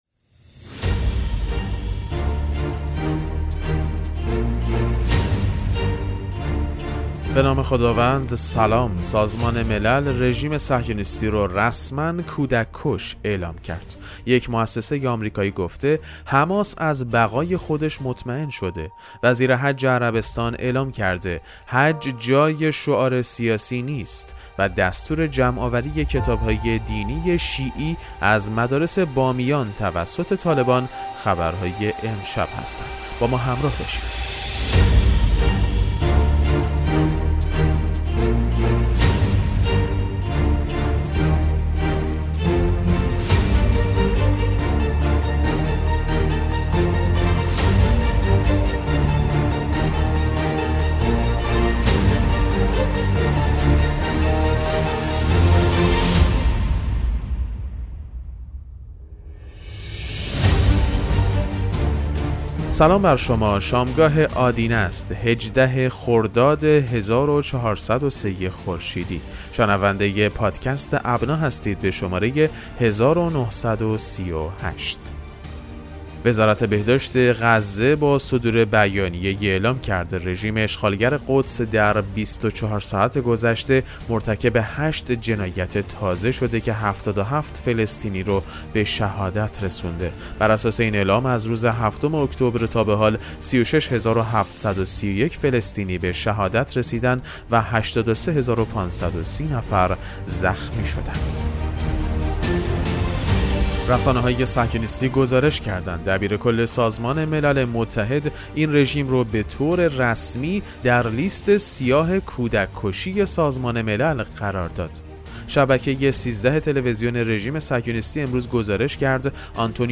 پادکست مهم‌ترین اخبار ابنا فارسی ــ 18 خرداد 1403